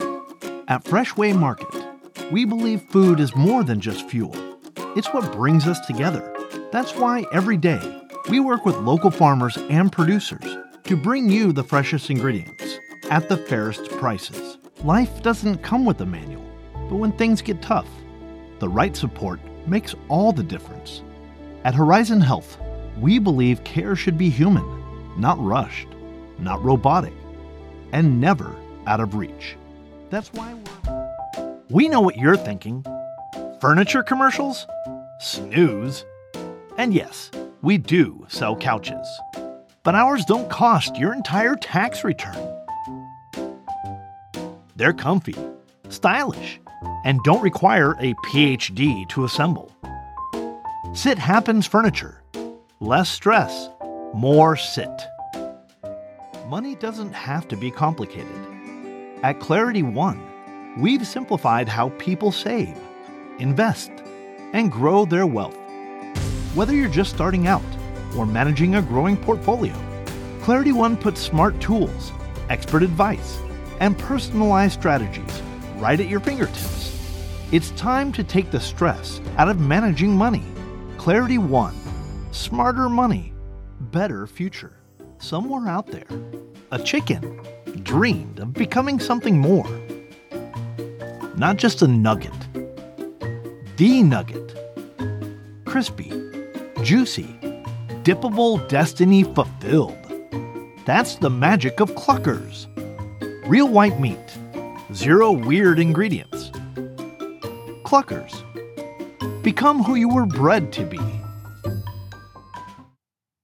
A versatile voice actor providing broadcast-quality narration and commercial voiceover from my professional home studio.
Commercial Demo
English - Western U.S. English
Middle Aged
I record from my home studio equipped using professional equipment to ensure clean, consistent, broadcast-quality audio on every project.